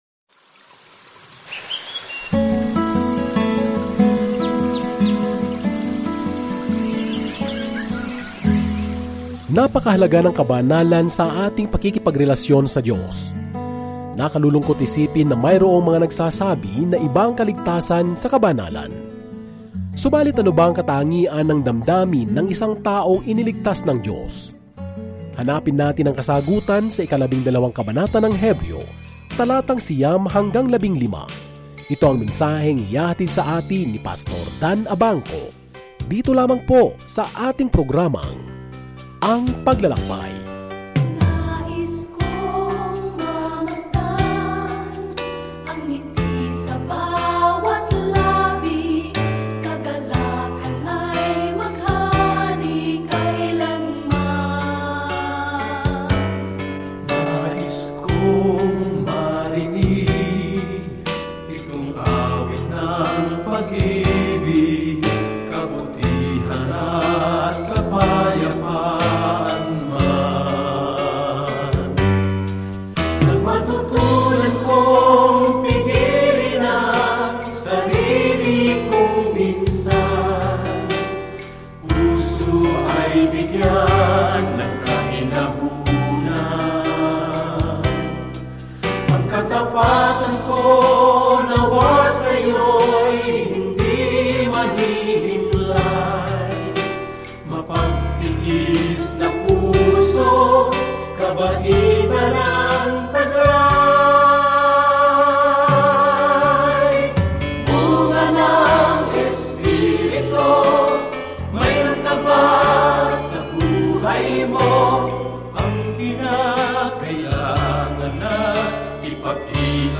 Araw-araw na paglalakbay sa Hebreo habang nakikinig ka sa audio study at nagbabasa ng mga piling talata mula sa salita ng Diyos.